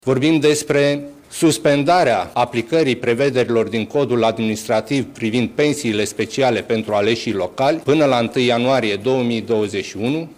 Luni seară, Cabinetul Orban adoptă o ordonanță de urgență. Ionel Dancă, șeful cancelariei premierului: